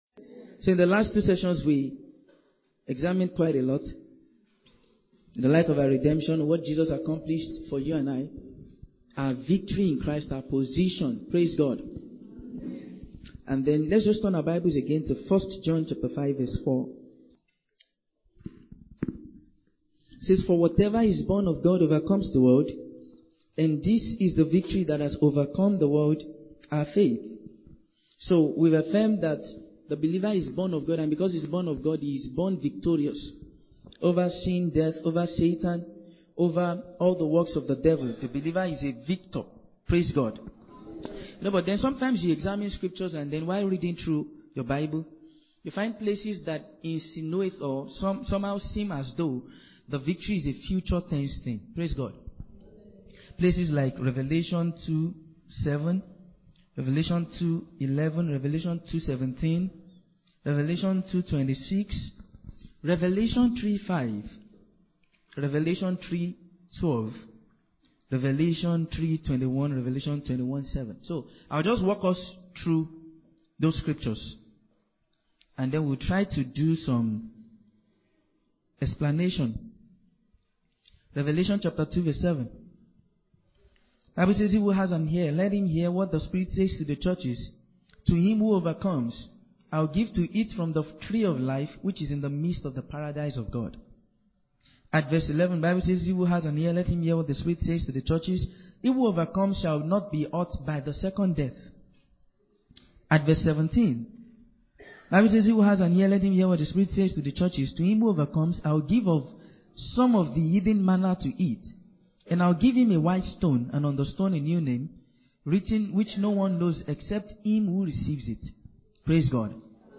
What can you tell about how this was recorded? Victory in Christ - Part 3 - TSK Church, Lagos